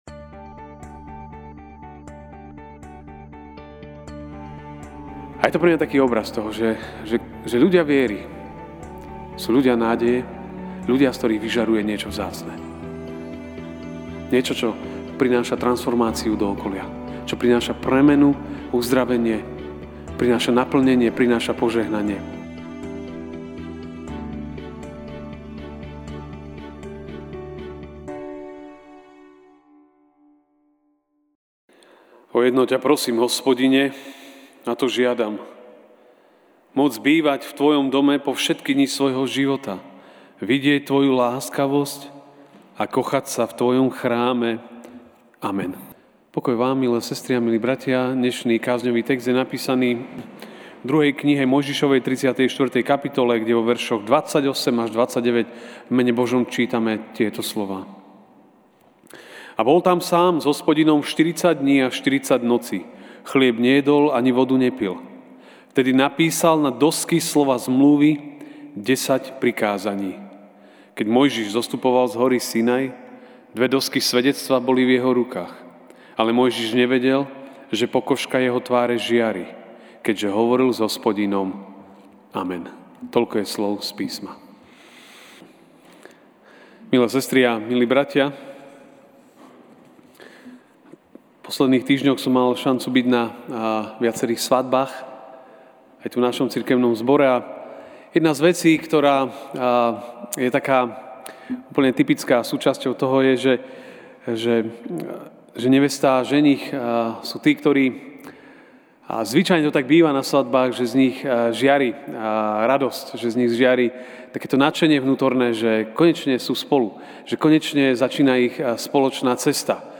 Večerná kázeň: Byť bližšie k Pánovi (2M 34, 28-29)